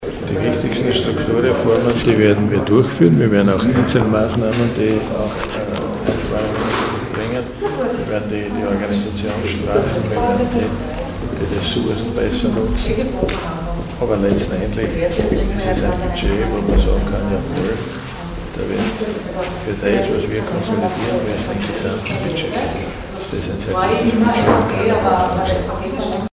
O-Ton: Budgetpräsentation Edlinger-Ploder und Schrittwieser
Oktober 2012).-  Heute (11.10.2012) präsentierten die beiden Landesräte Kristina Edlinger-Ploder und Siegfried Schrittwieser im Medienzentrum Steiermark ihre Ressortdoppelbudgets für die Jahre 2013 und 2014.